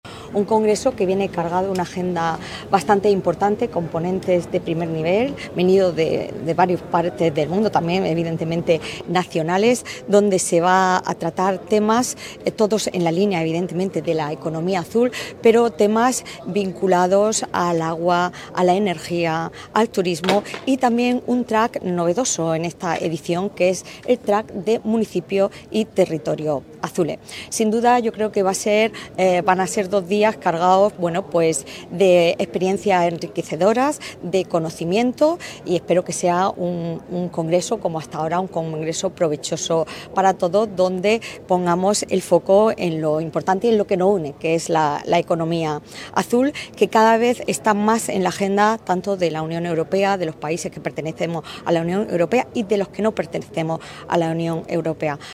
SACRAMENTO-SANCHEZ-INAUGURACION-SUNBLUE.mp3